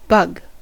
bug: Wikimedia Commons US English Pronunciations
En-us-bug.WAV